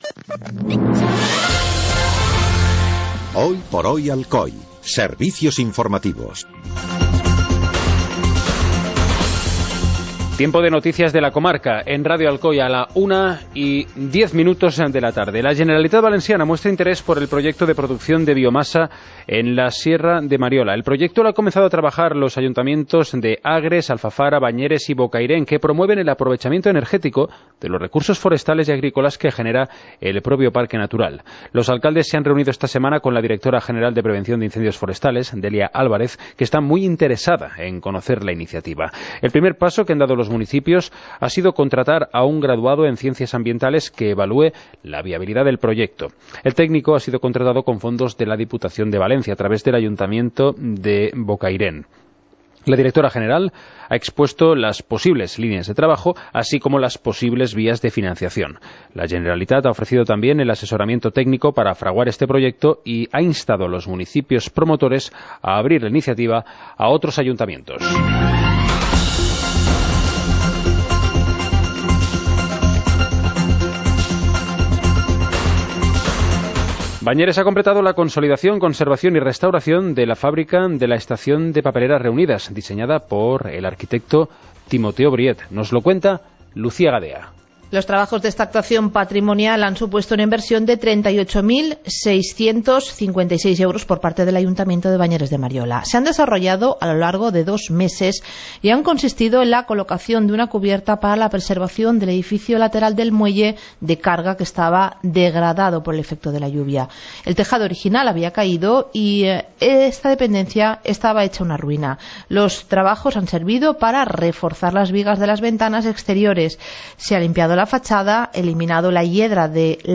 Informativo comarcal - viernes, 27 de enero de 2017